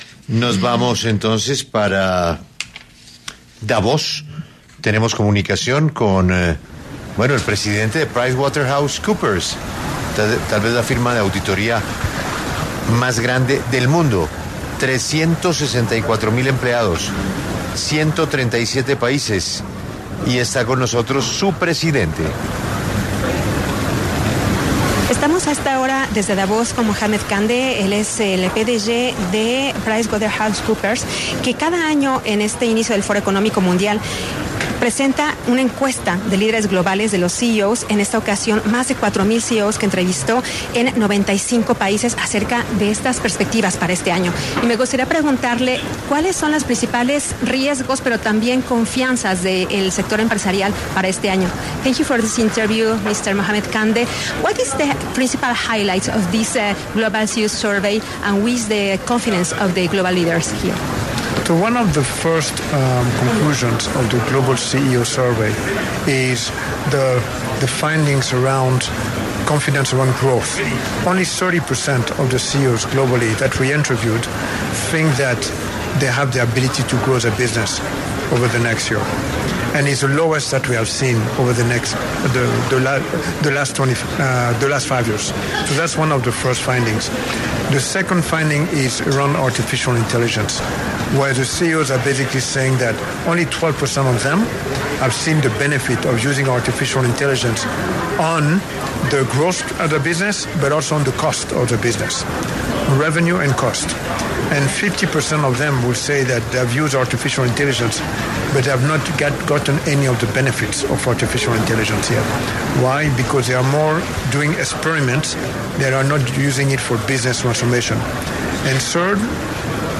Mohamed Kande, presidente global actual de PwC (PricewaterhouseCoopers), habló para los micrófonos de 6 AM W desde el Foro de Davos sobre los principales riesgos y confianzas del sector empresarial para este año.